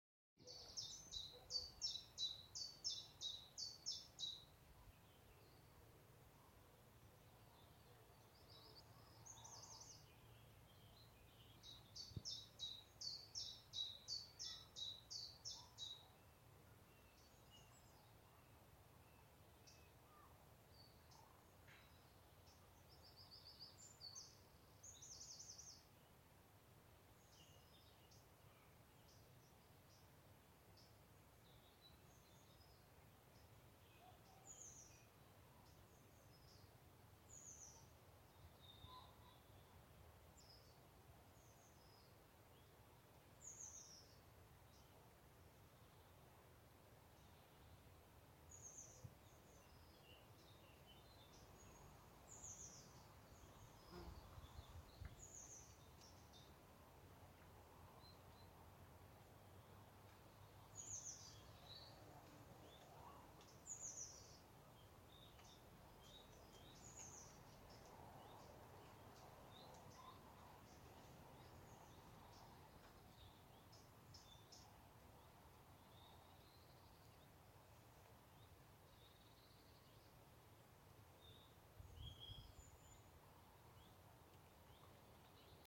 Zilpzalp Ruf
Zilpzalp-Ruf-Voegel-in-Europa.mp3